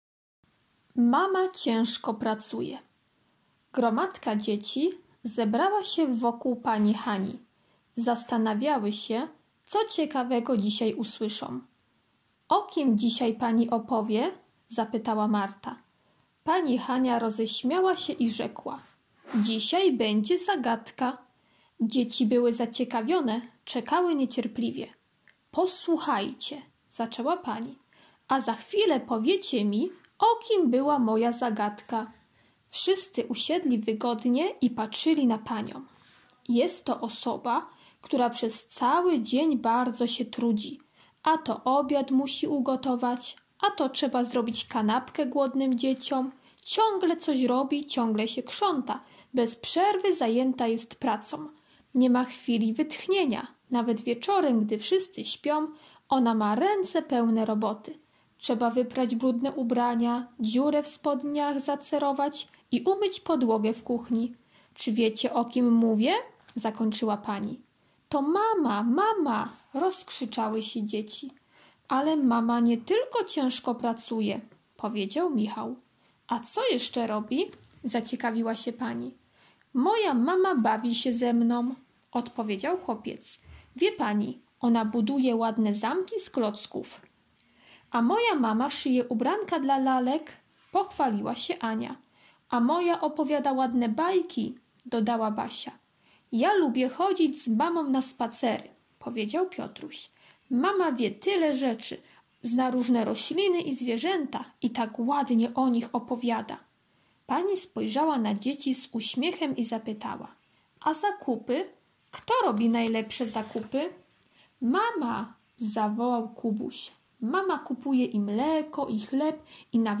wtorek - opowiadanie o mamie [5.76 MB] wtorek - ćw. dla chętnych - karta pracy "Dokończ rysunek mamy" [80.50 kB] wtorek - ćw. dla chętnych - litera M, m [30.91 kB]